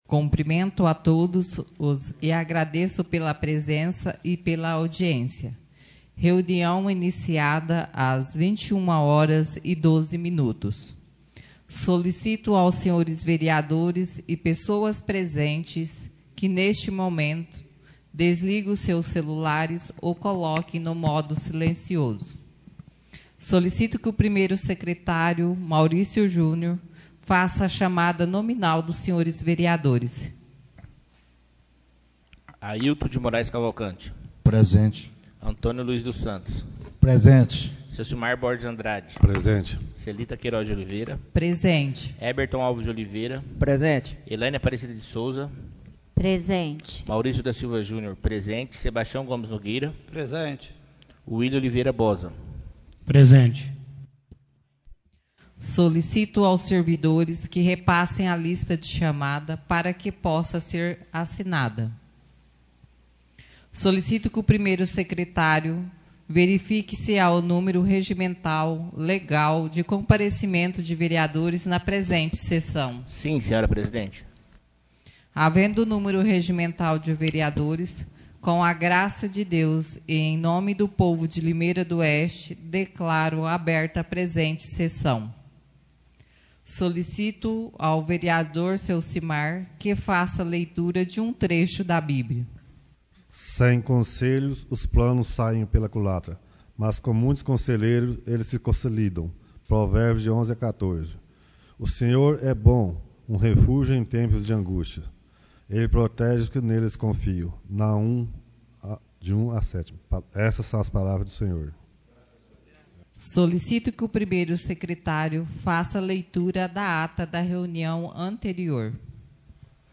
Reuniões Ordinárias